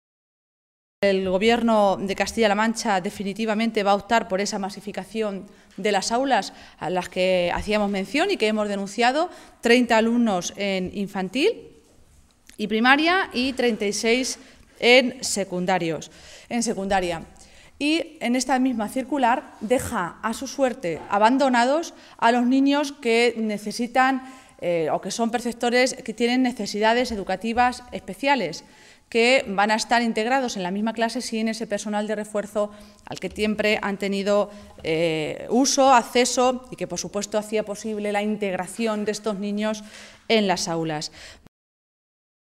Así se pronunciaba Maestre en una comparecencia ante los medios de comunicación en la que decía que “en las cinco tristes páginas que tiene esa circular se pone por escrito, negro sobre blanco, lo que la señora Cospedal y el consejero luego niegan o matizan en sus declaraciones, y es el desmantelamiento de la educación pública en Castilla-La Mancha”, señalaba.